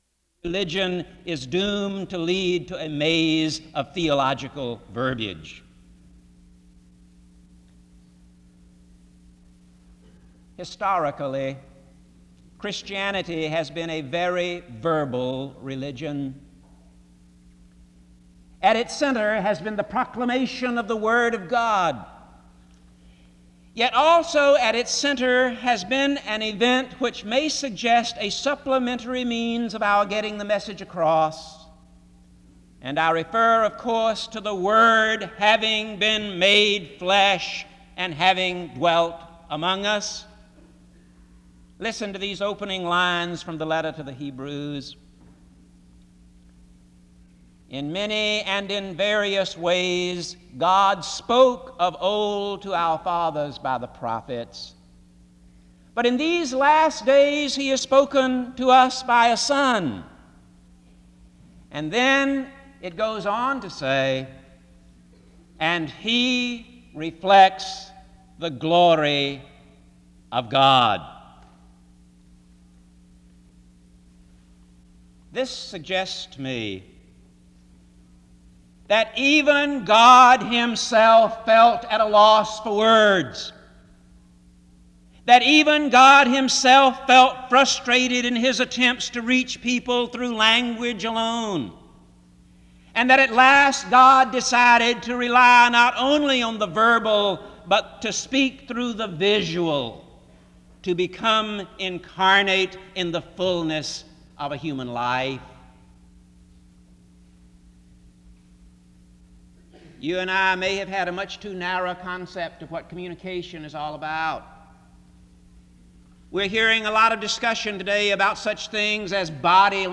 SEBTS Commencement
Location Wake Forest (N.C.)